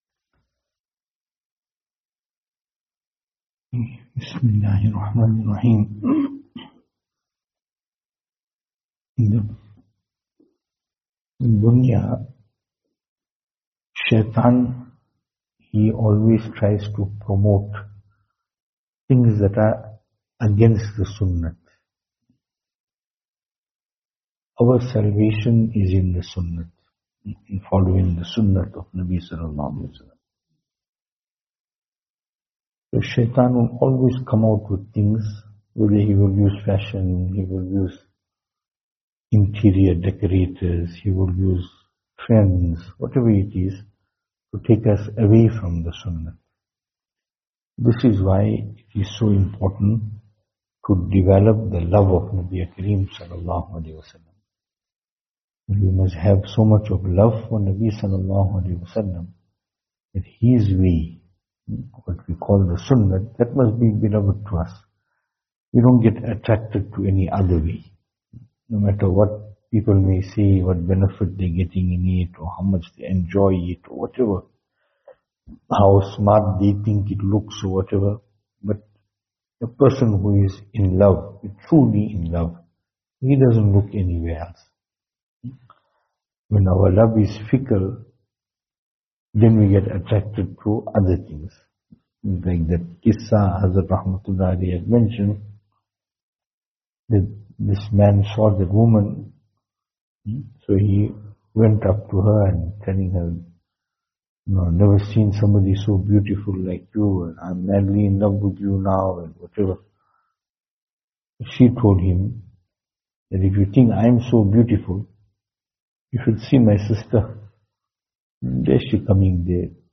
After Esha Majlis